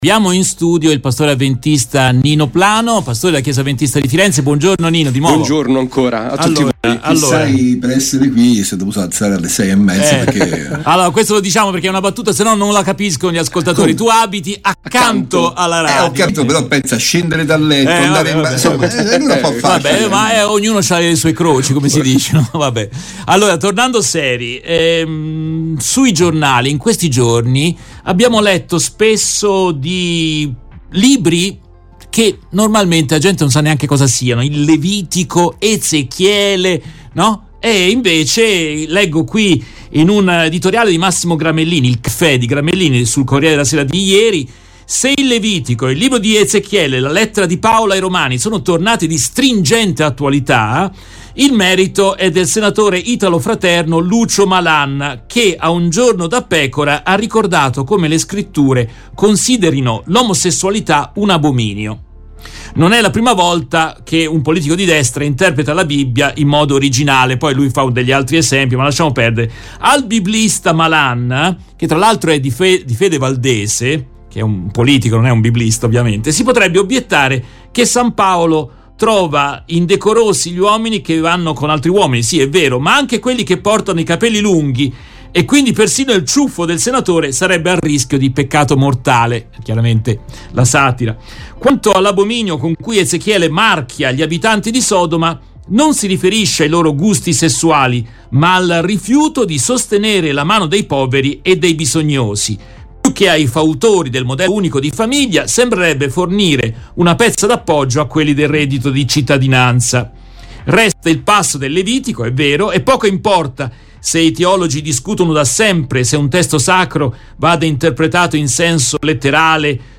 intervistato
nel corso della diretta RVS del 25 novembre 2022.